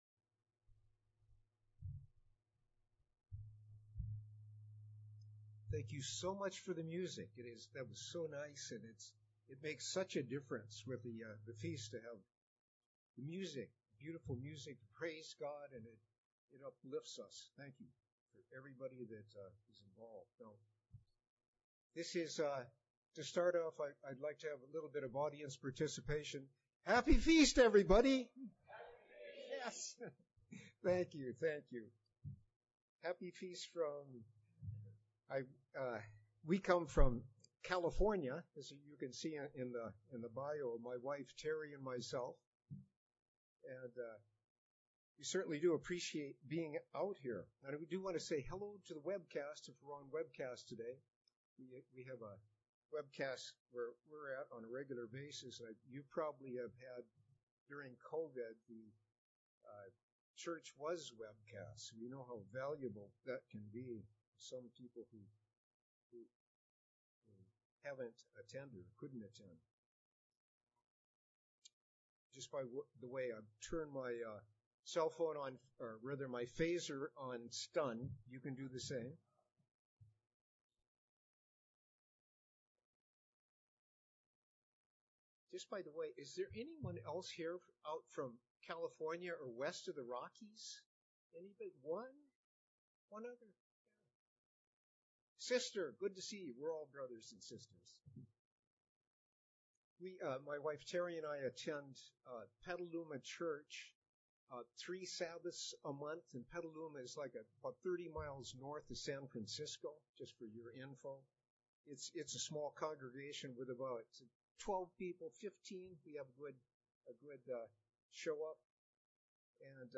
This sermon was given at the Pewaukee, Wisconsin 2022 Feast site.